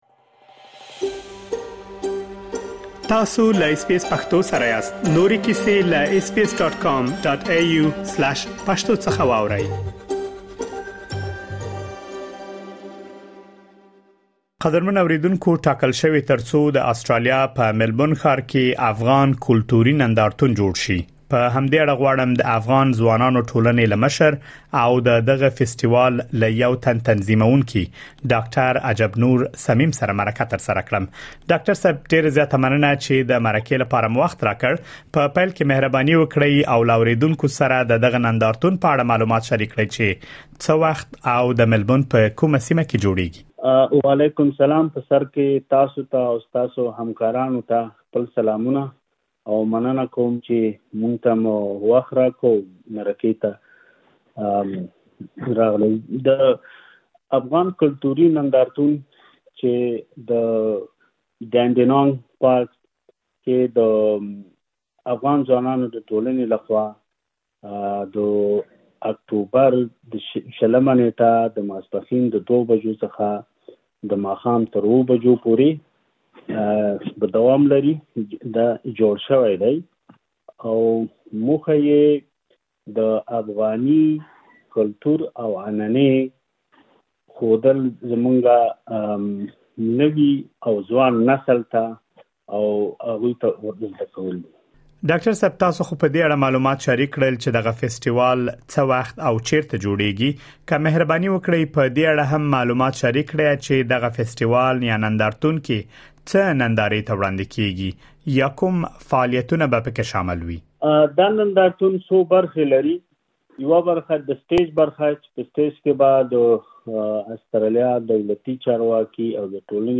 تاسو کولی شئ لا ډېر معلومات په ترسره شوې مرکې کې واورئ.